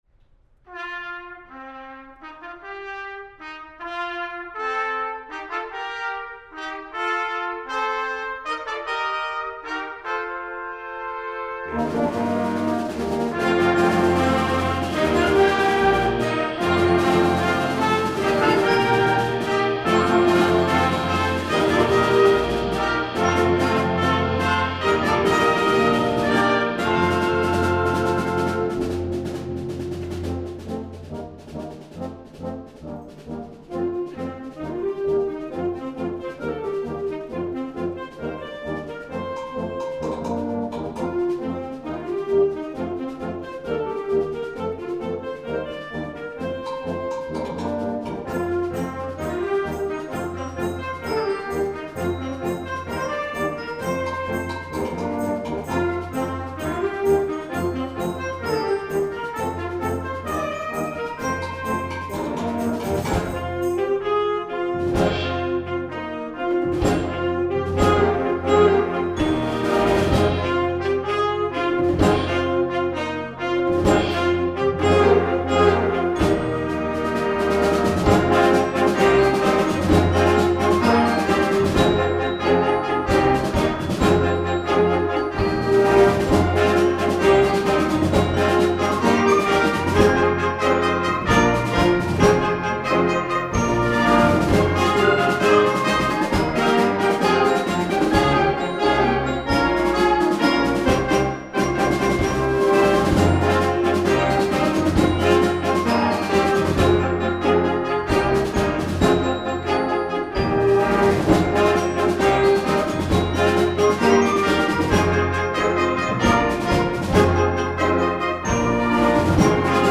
KONZERTMUSIKBEWERTUNGEN - JUGENDBLASORCHESTER
>live